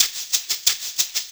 Shaker 04.wav